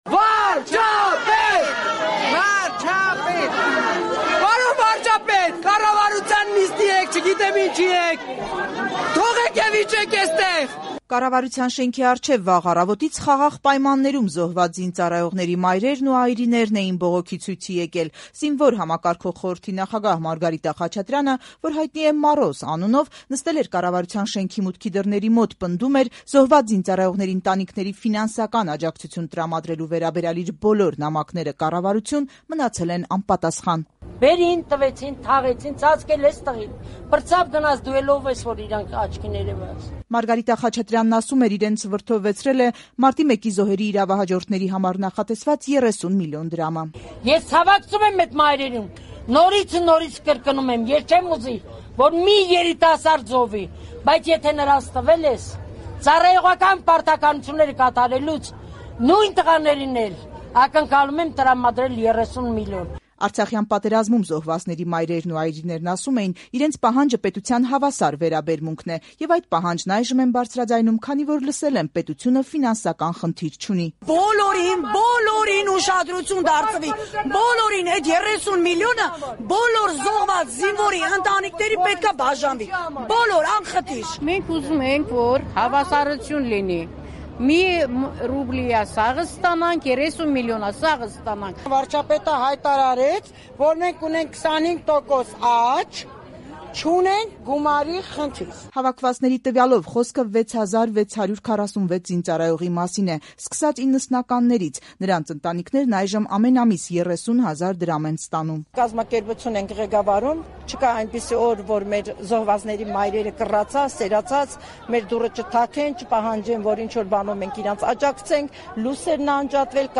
Ռեպորտաժներ
«Պետությունը պետք է բոլորին ուշադրություն դարձնի». զոհված զինծառայողների մայրերն ու այրիները՝ կառավարության շենքի դիմաց